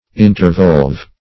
Search Result for " intervolve" : The Collaborative International Dictionary of English v.0.48: intervolve \in`ter*volve"\, v. t. [imp.